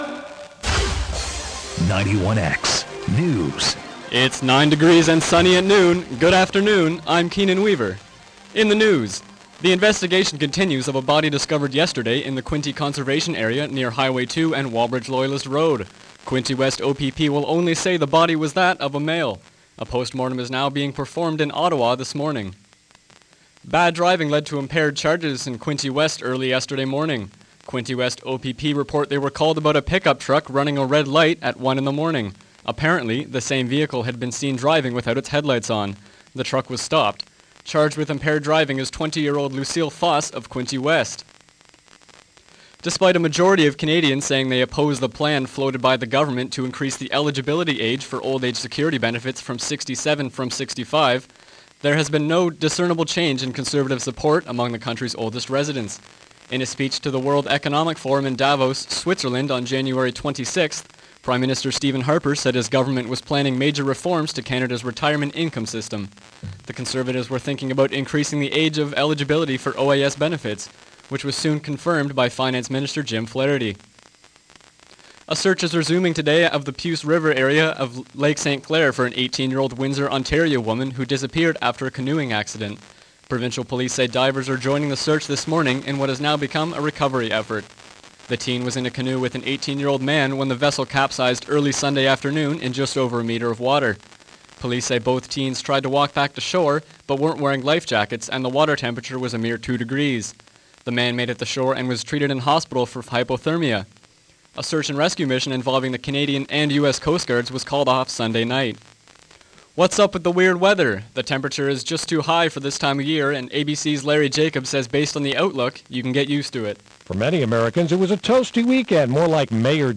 The investigation continues after a male body was found in the Quinte Conservation area near Wallbridge-Loyalist Road on Sunday. Bad driving led to impaired charges in Quinte West. Spring like weather blankets North America and some places reach record highs. For these stories sports and more listen to the 12pm 91X newscast.